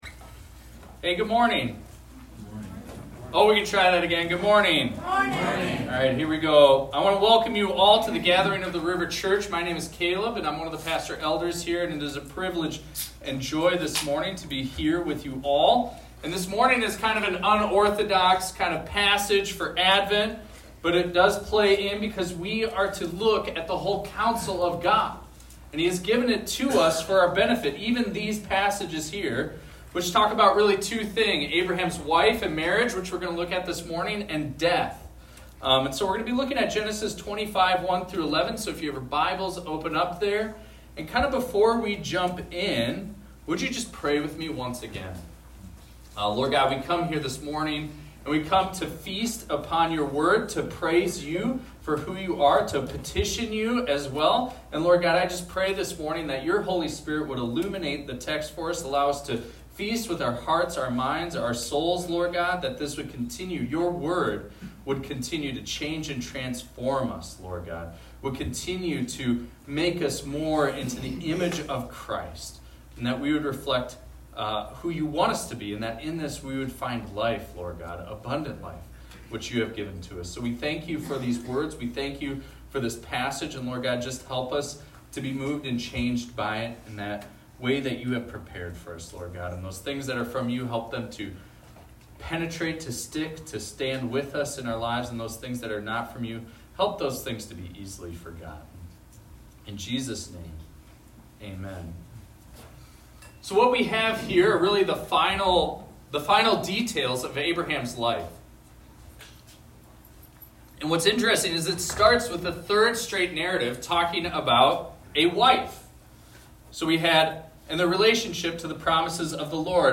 This is a recording of a sermon titled, "The Death of Abraham."